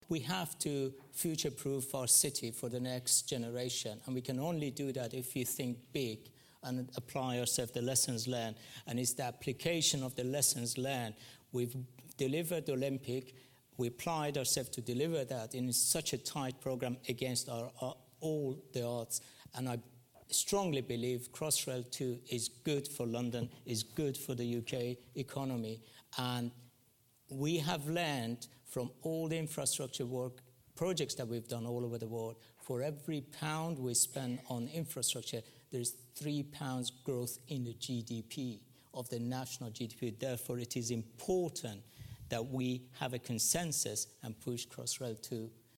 told the London Infrastructure Summit that we need to use that experience to future-proof the Capital with schemes like Crossrail.